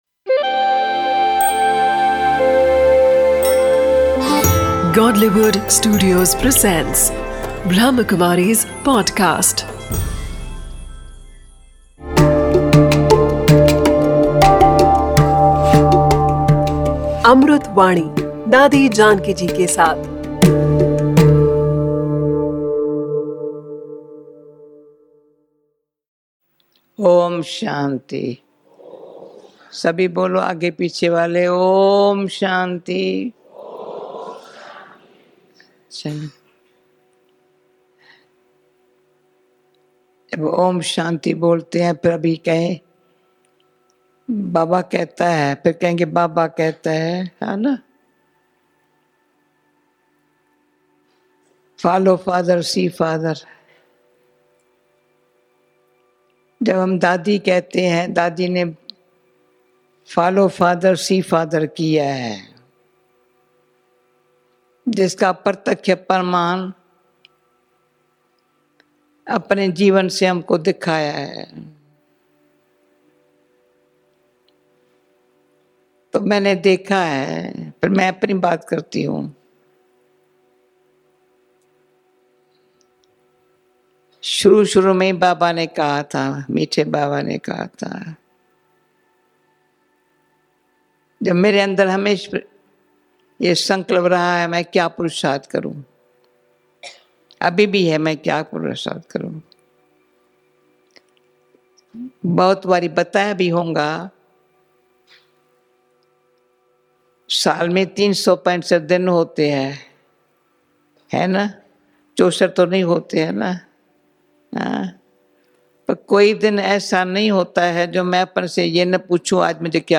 'Amrut Vani' is a collection of invaluable speeches of our dearest Dadi Janki ji.